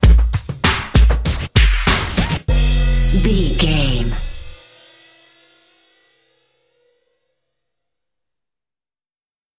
Ionian/Major
drum machine
synthesiser
funky
hard hitting